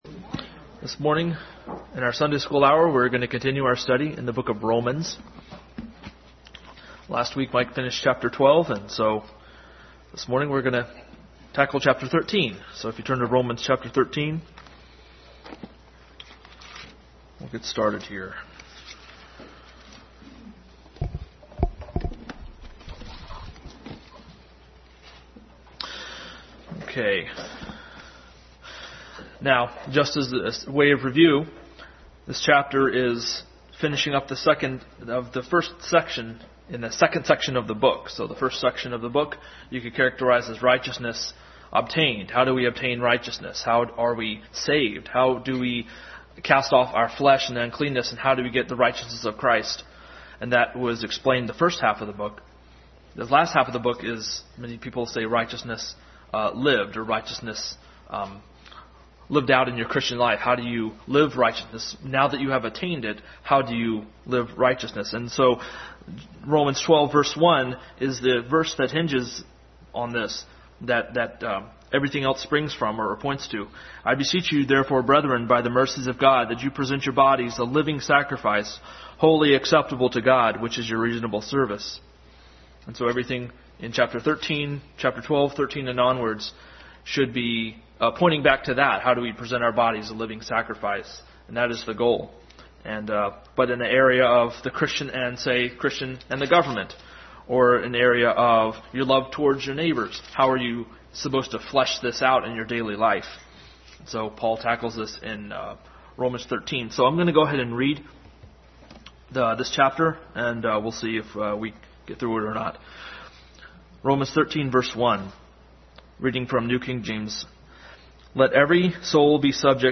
Adult Sunday School Class continued study in Romans.